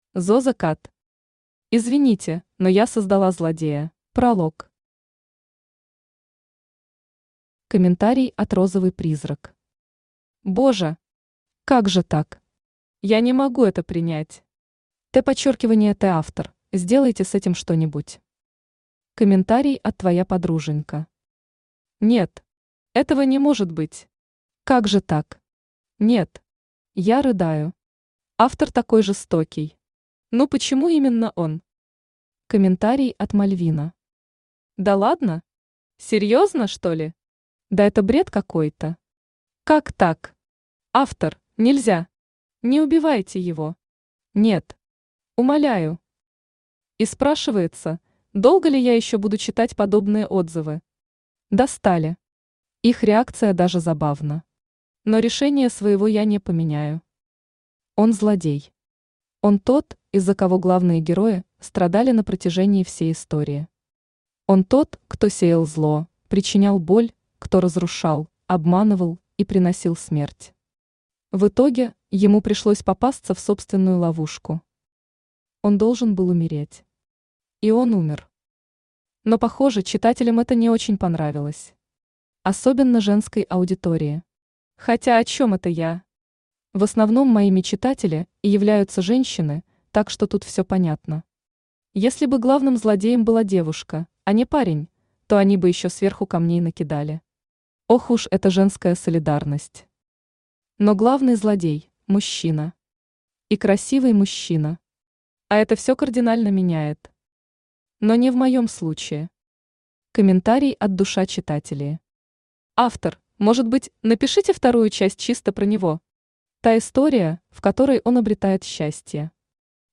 Автор Зозо Кат Читает аудиокнигу Авточтец ЛитРес.